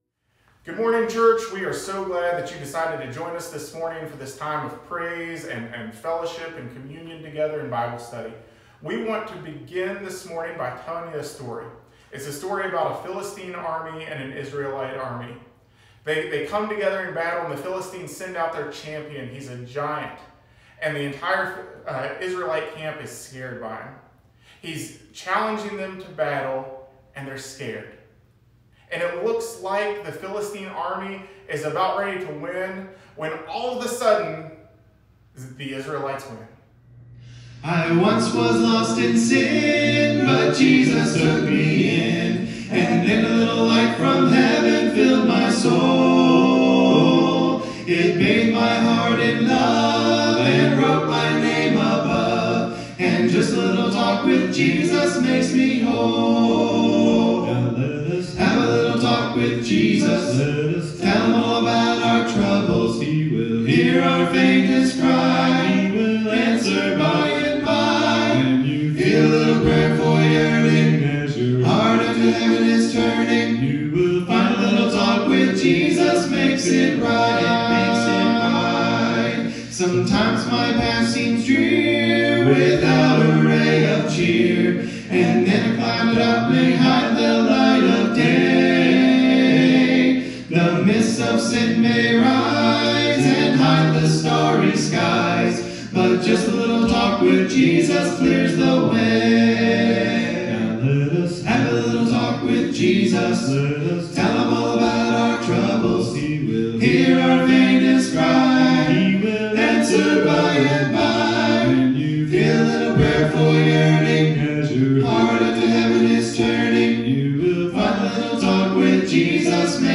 Date of Sermon